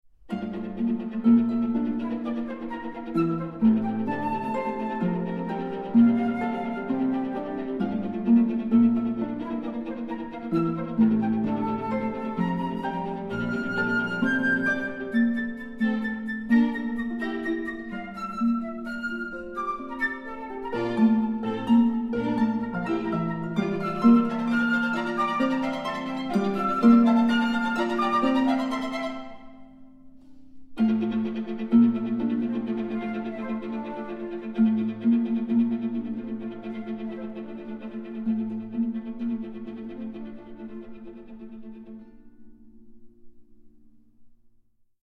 for flute, viola and harp
Calm and still
Lively